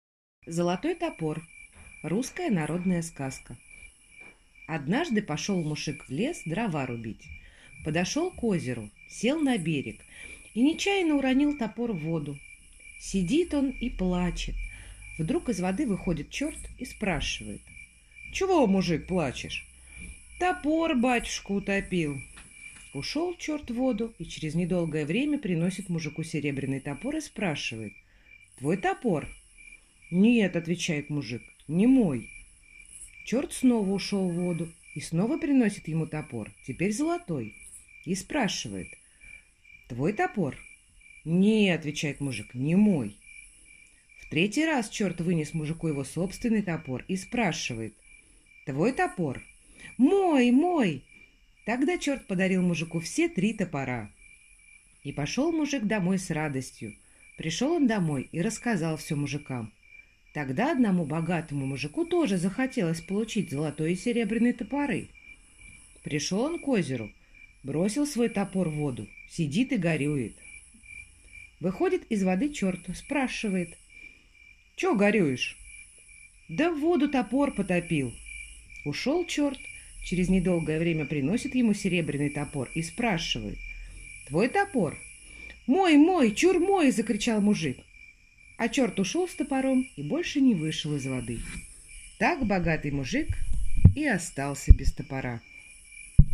Золотой топор. Русская народная сказка